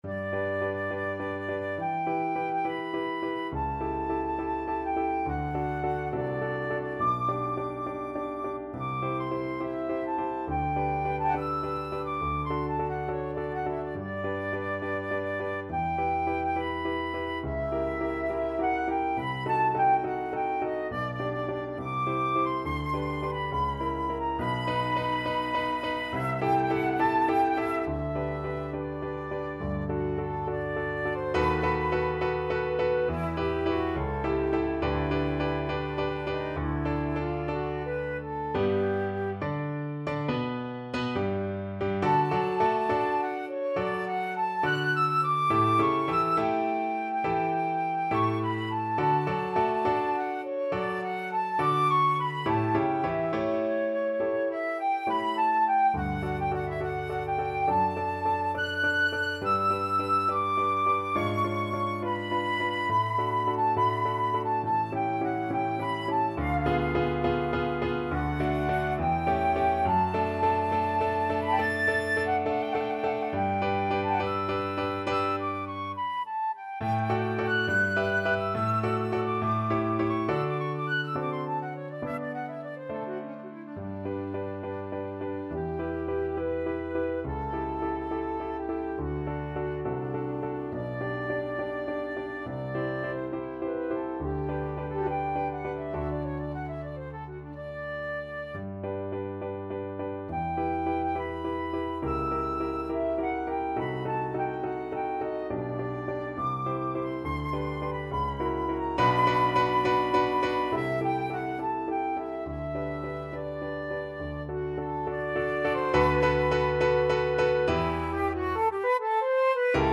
Classical Paganini, Niccolò Cantabile Op.17 Flute version
Flute
G major (Sounding Pitch) (View more G major Music for Flute )
4/4 (View more 4/4 Music)
Classical (View more Classical Flute Music)